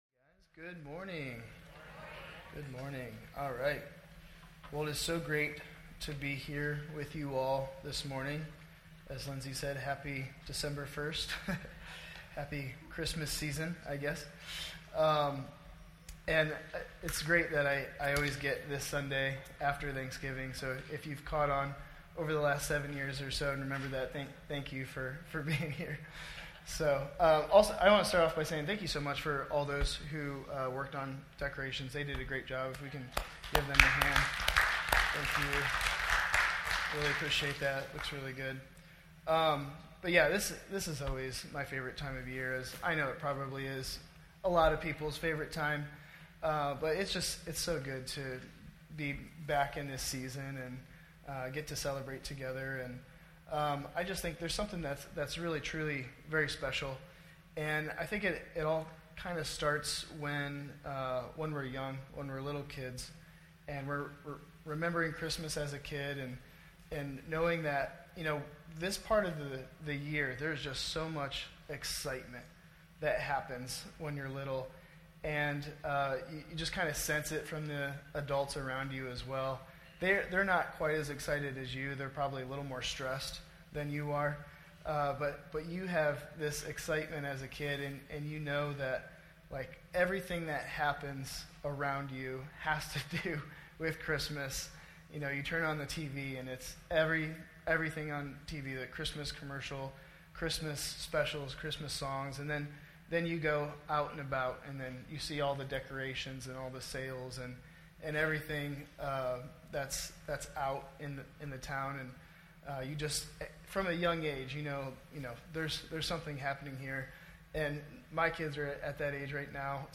sermon_audio_mixdown_12_1_24.mp3